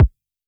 RDM_Copicat_SY1-Kick04.wav